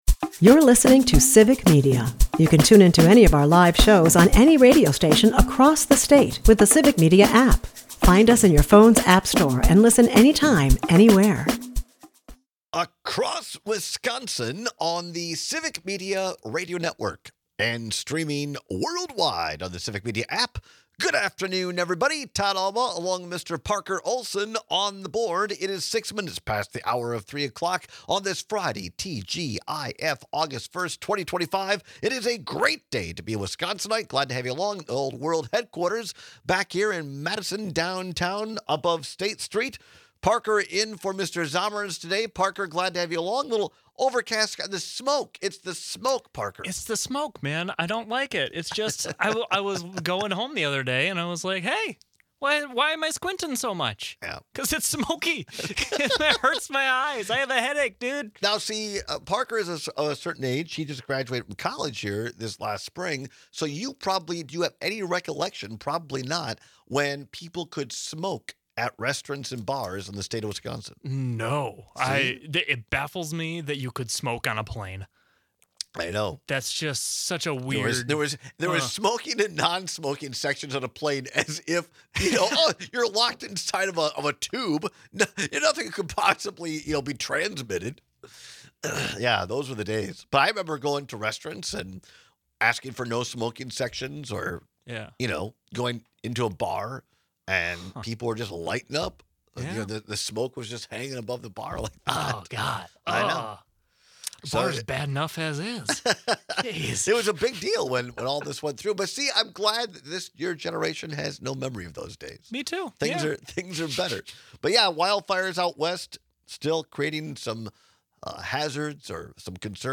strong anti-malort takes from the listeners
airs live Monday through Friday from 2-4 pm across Wisconsin.